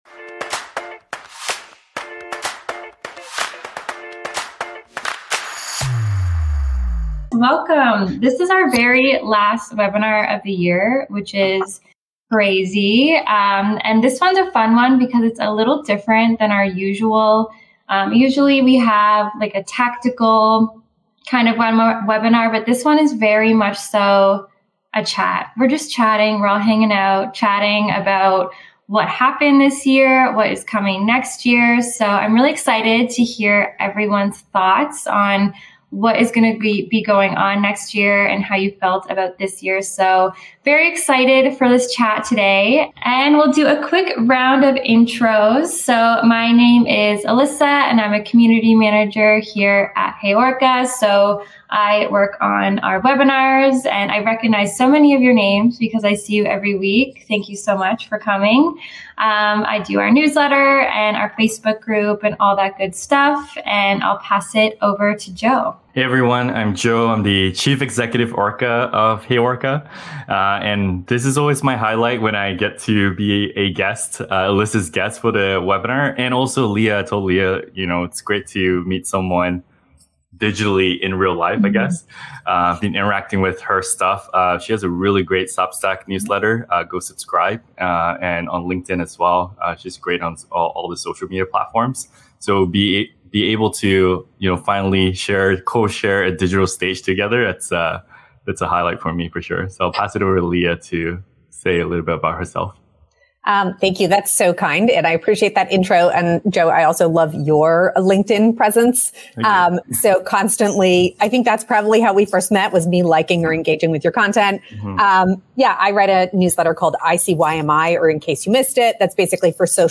This year, we have two very special guests joining us!